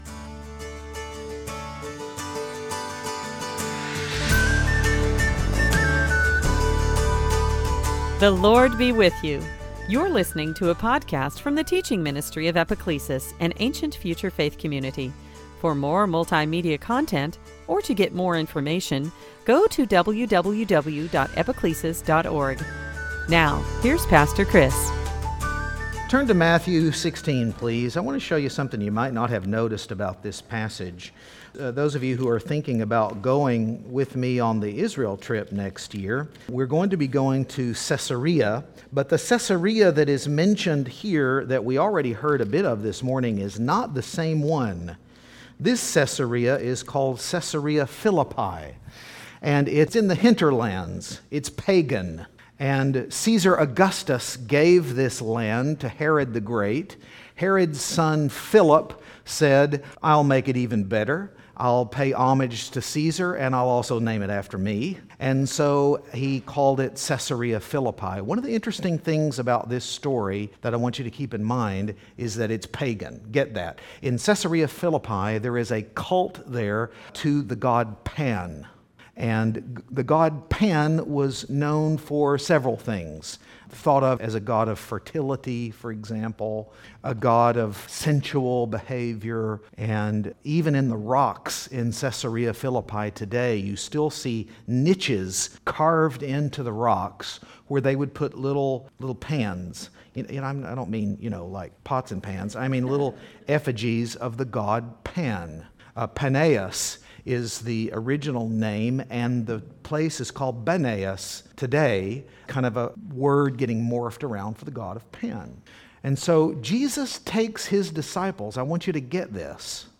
Service Type: Season after Pentecost